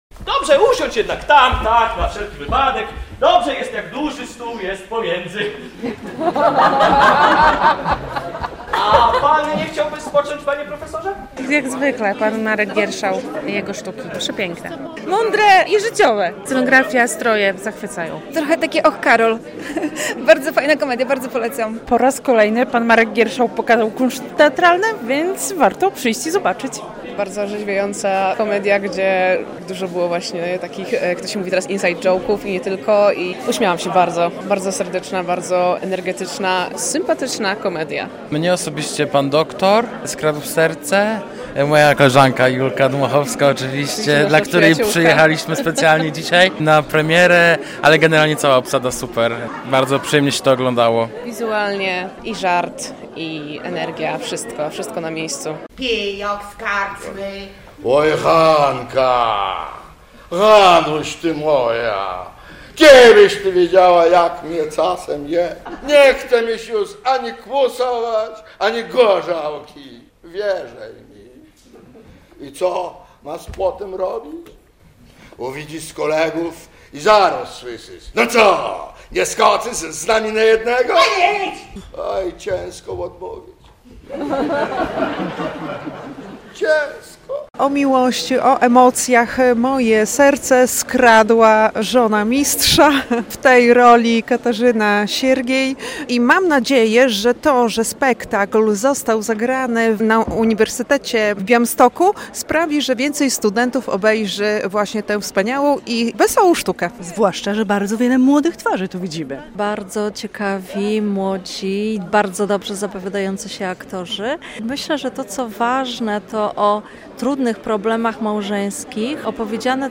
Wrażenia widzów